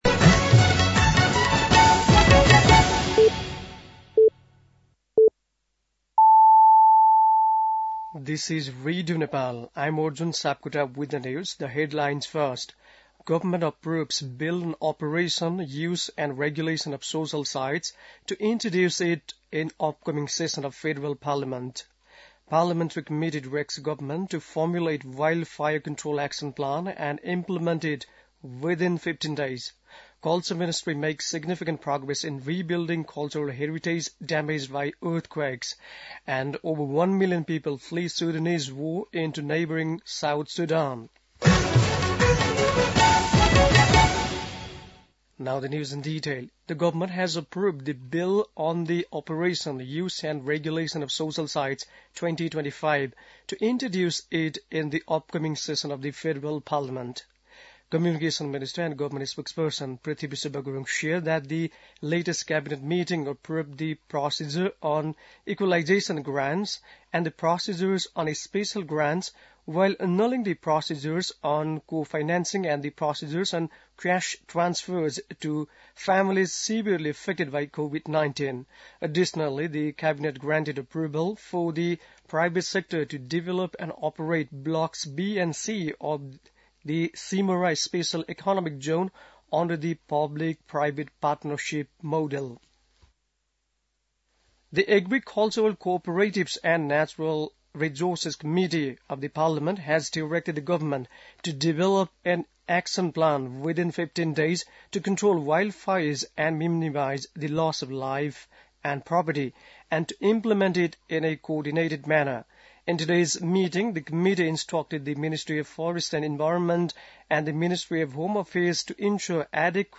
बेलुकी ८ बजेको अङ्ग्रेजी समाचार : १० माघ , २०८१
8-pm-english-news-10-09.mp3